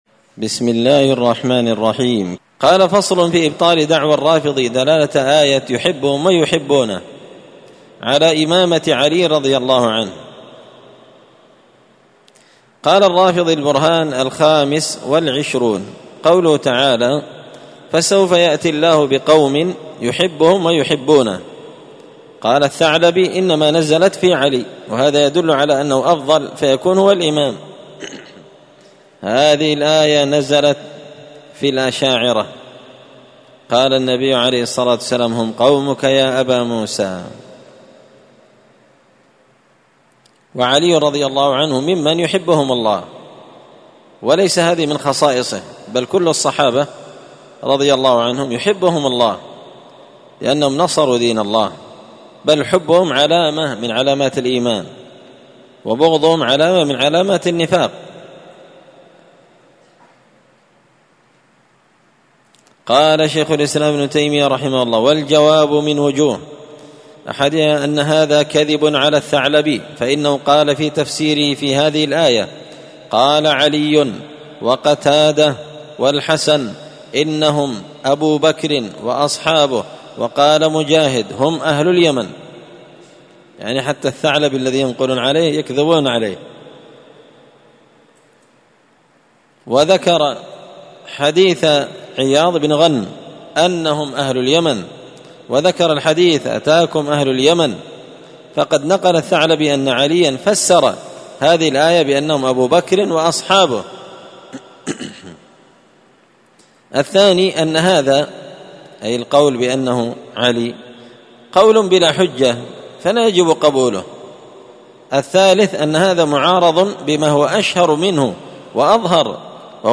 الأثنين 12 صفر 1445 هــــ | الدروس، دروس الردود، مختصر منهاج السنة النبوية لشيخ الإسلام ابن تيمية | شارك بتعليقك | 90 المشاهدات
مسجد الفرقان قشن_المهرة_اليمن